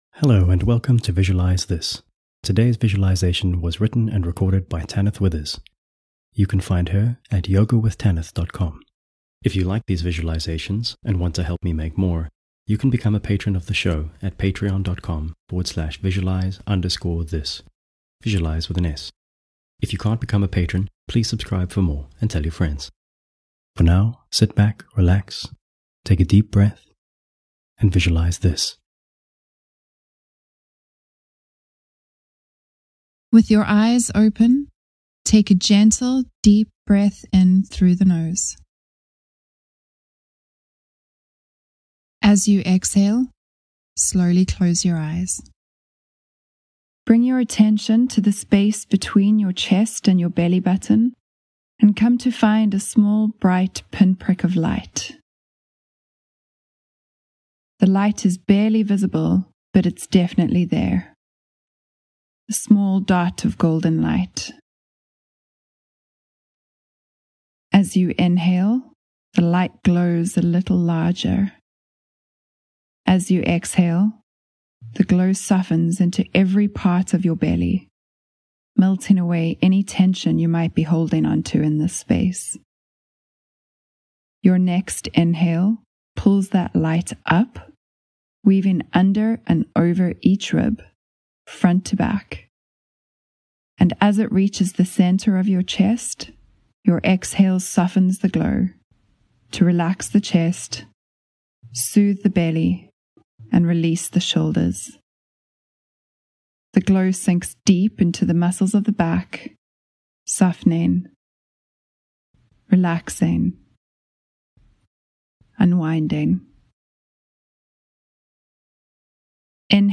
This is a perfect 6-minute visualisation for those who want to share a little light with the world right now.